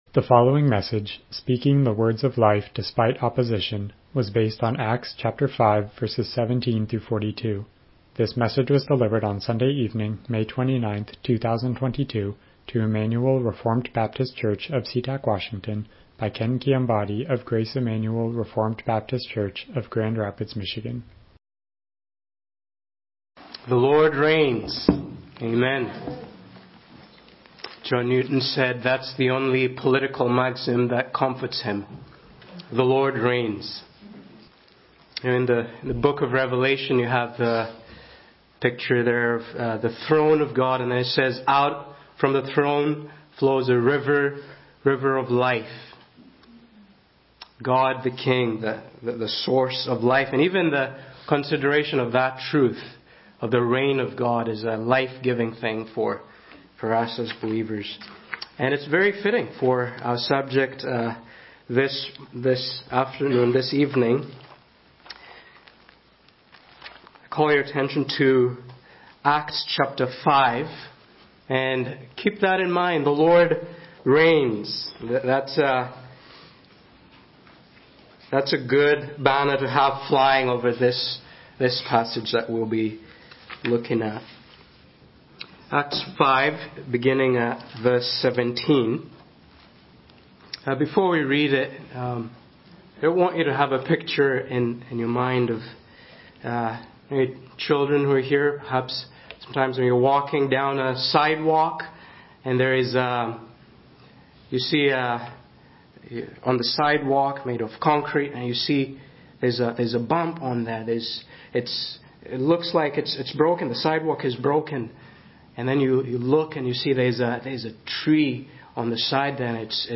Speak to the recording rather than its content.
Passage: Acts 5:17-42 Service Type: Evening Worship « Praise God for the New Birth Robert Murray M’Cheyne